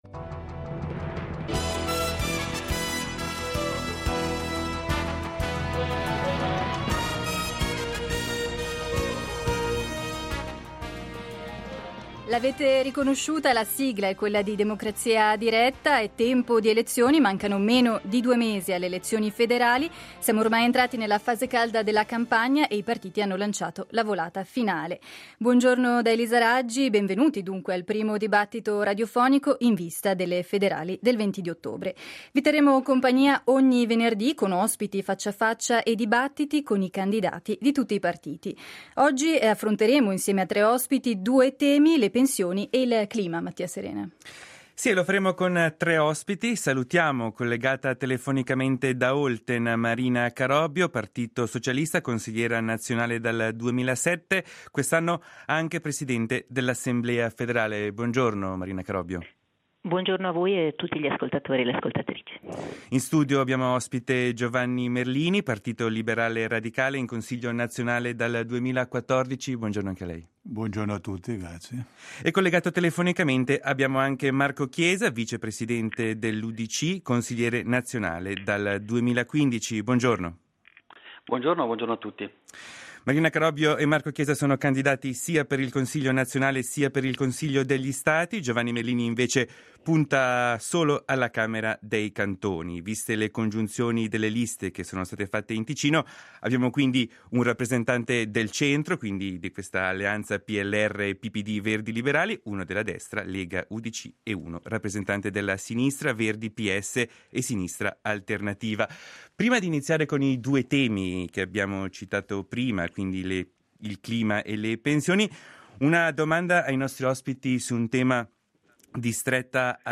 È tempo di elezioni, proponiamo primo dibattito radiofonico in vista delle Federali del 20 ottobre. A 44 giorni dal rinnovo del Parlamento svizzero, proponiamo un confronto con i candidati di tre partiti.